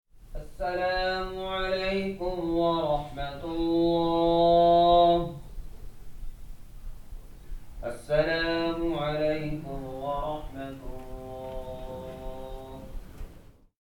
Salat
salat.mp3